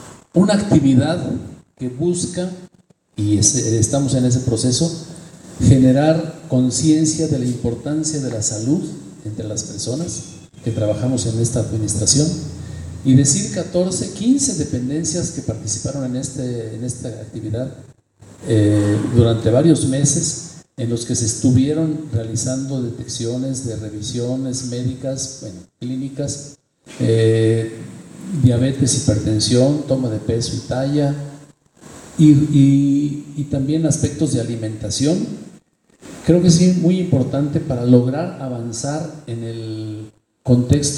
Eduardo Tovar, director de salud municipal